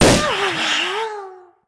balloon_ghost_pop_01.ogg